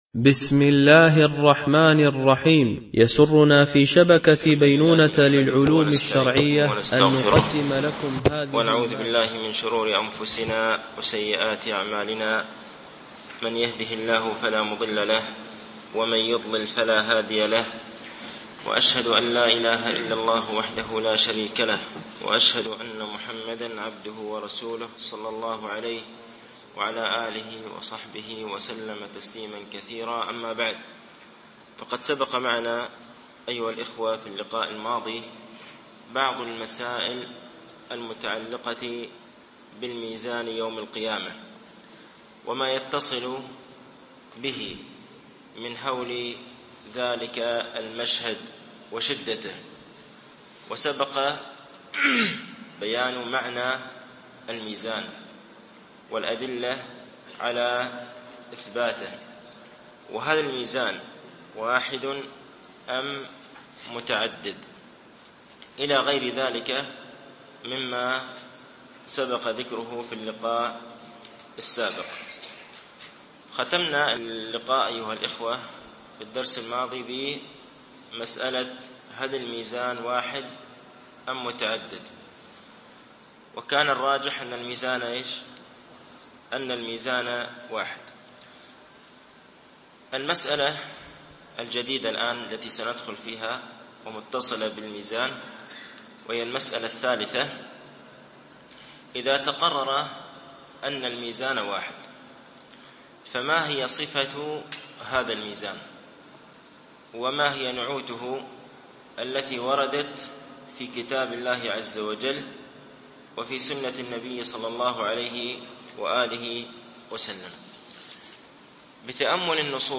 شرح مقدمة ابن أبي زيد القيرواني ـ الدرس السادس و الأربعون